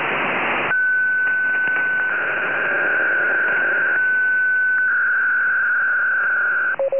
qpsk_10158.wav